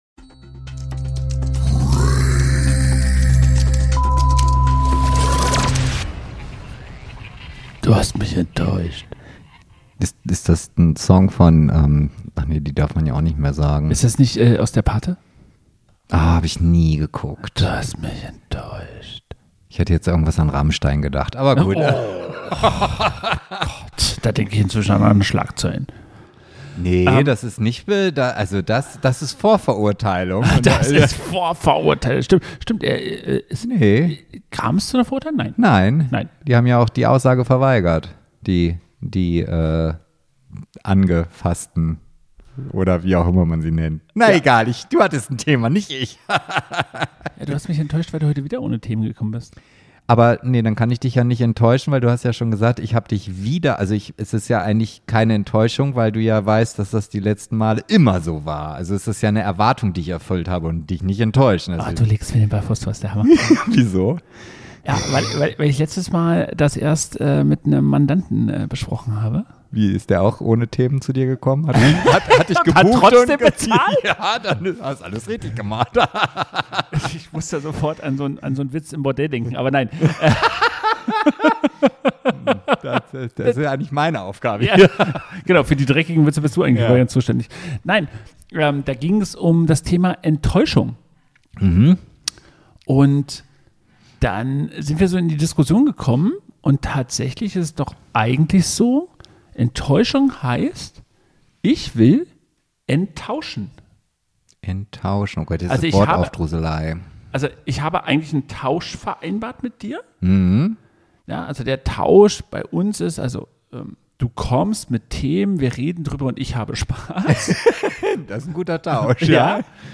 Warum ist Enttäuschung oft kein Fehler, sondern ein Zeichen dafür, dass ein Deal für eine Seite einfach nicht mehr stimmt? Zwischen philosophischen Gedankengängen, Coach-Anekdoten und spontanen Lachern geht’s um Verantwortung, Erwartungen – und um die Frage, wer hier eigentlich wen enttäuscht.